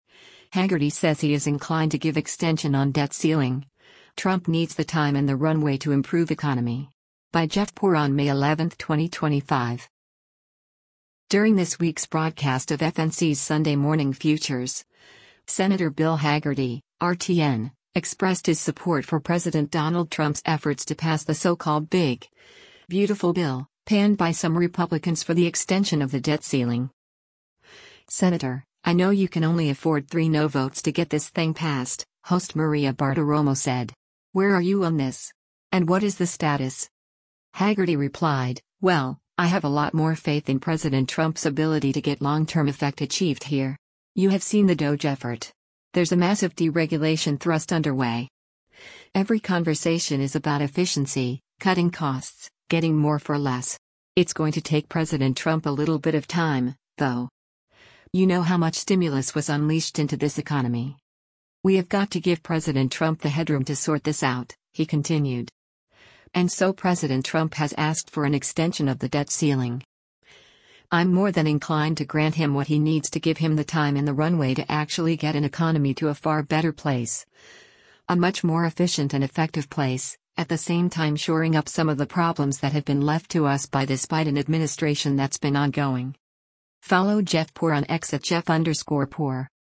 During this week’s broadcast of FNC’s “Sunday Morning Futures,” Sen. Bill Hagerty (R-TN) expressed his support for President Donald Trump’s efforts to pass the so-called “big, beautiful bill,” panned by some Republicans for the extension of the debt ceiling.